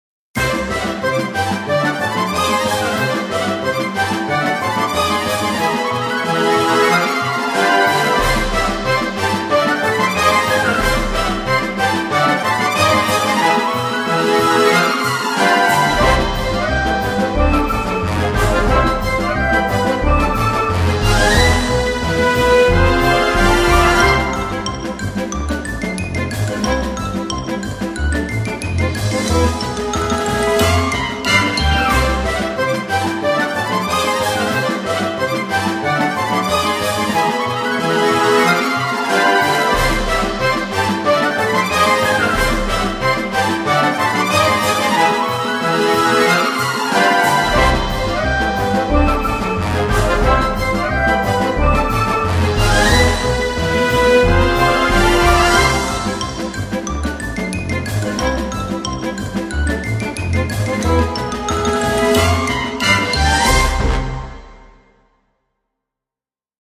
Музыка для активных действий клоунов